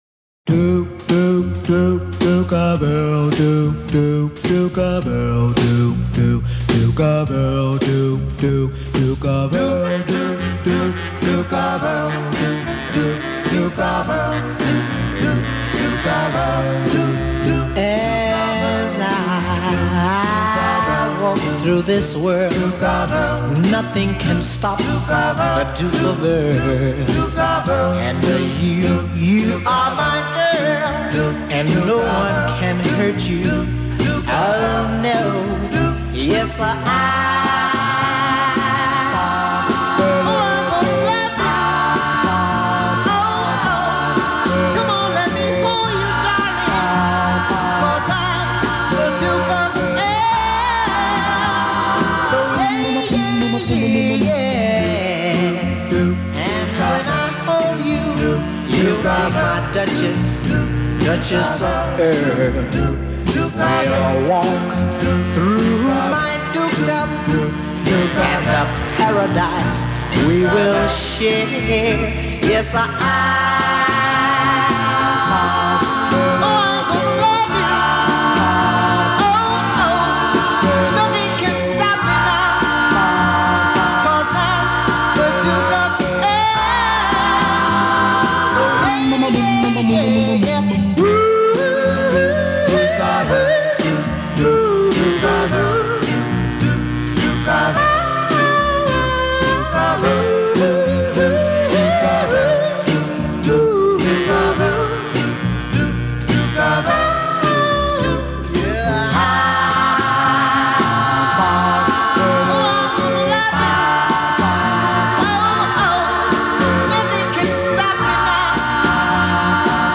Doo-wop